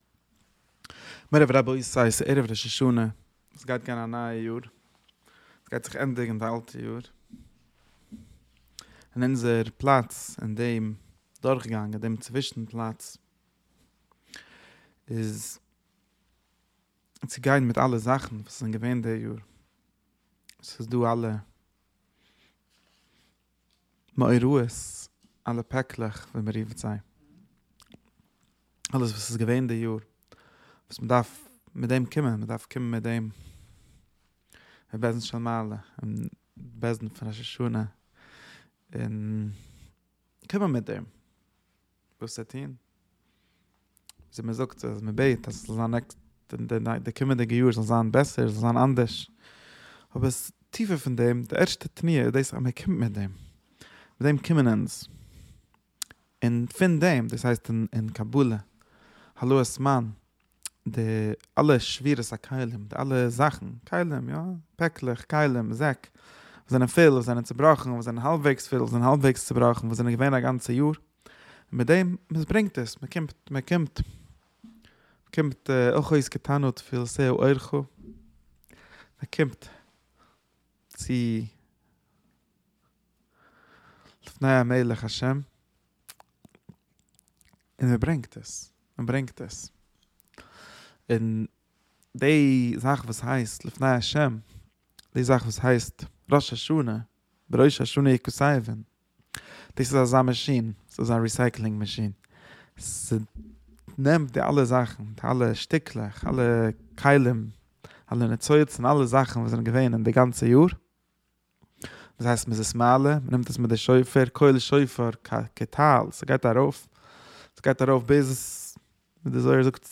שיעור שבועי